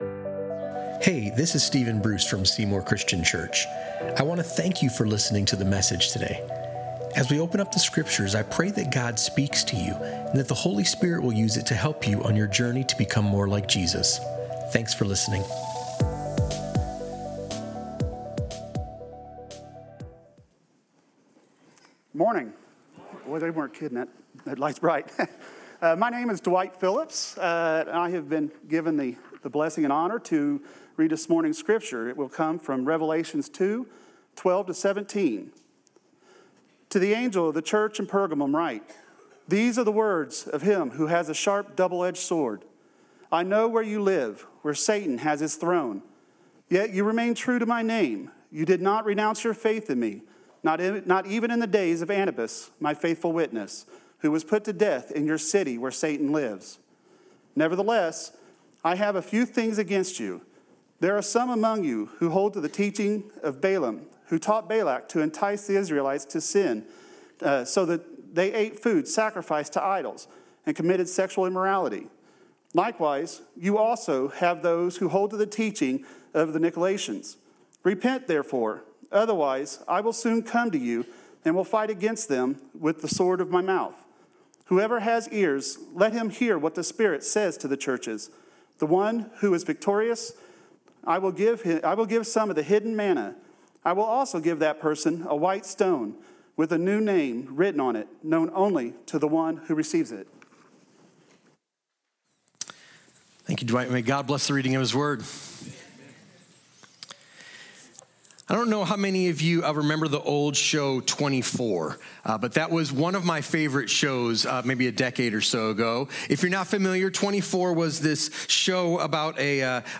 Revelation 2:12–17 Sermon